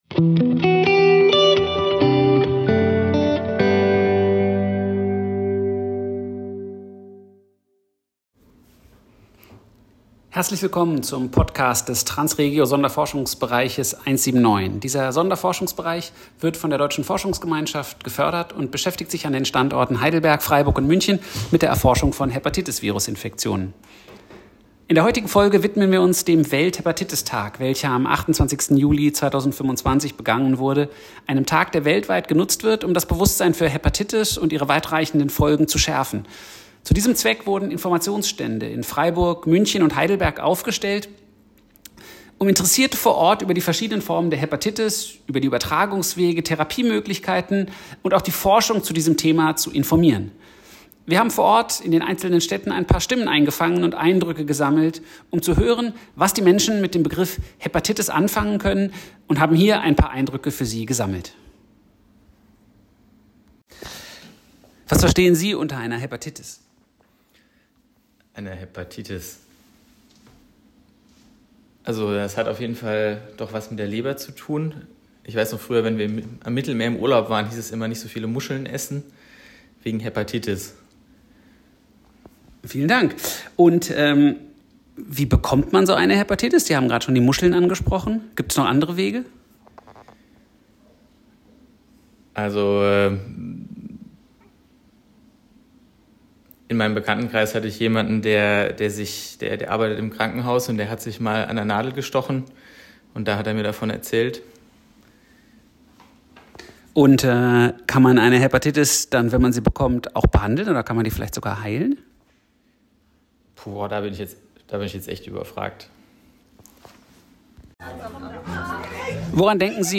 Experten beantworteten Fragen und stellten Informationen zur Verfügung. Wir haben Besucher interviewt und hier einen kleinen Ausschnitt der Umfragen an den Standorten zusammengestellt.